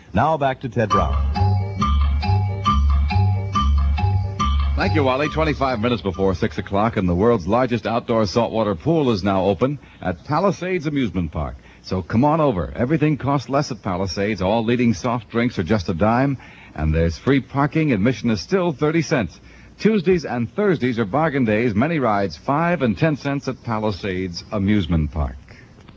WNEW commercial